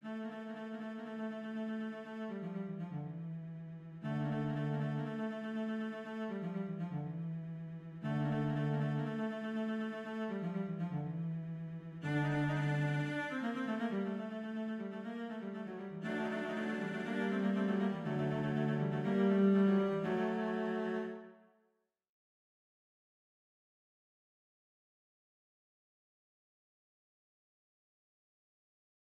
Pour des raisons de clarté auditive, les exemples audios seront ici donnés avec des sons de violoncelle, ceux ci étant préférables aux sons de voix synthétiques.
La suite sera une répétition avec amplification de ce qui a été dit dans la première partie à deux voix, la vocalise sur la mot « Sanctus » passant d’une voix à l’autre.